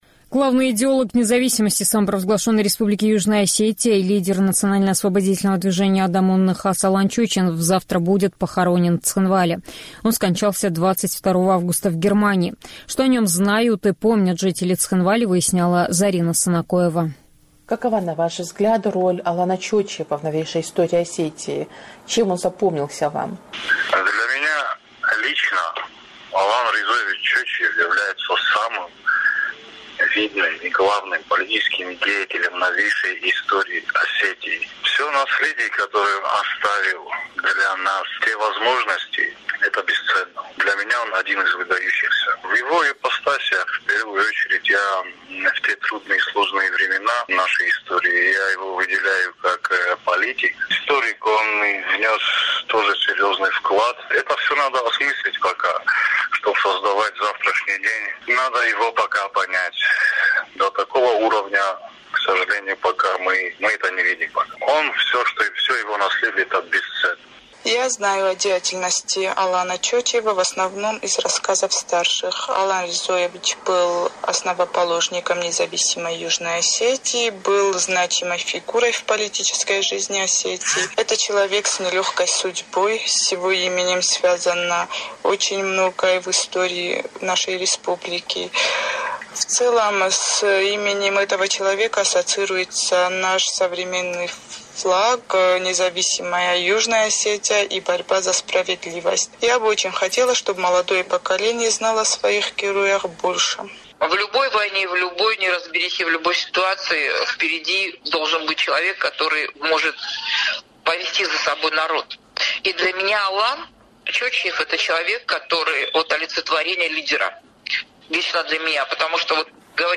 О роли Алана Чочиева в новейшей истории «Эхо Кавказа» спросило у жителей Цхинвали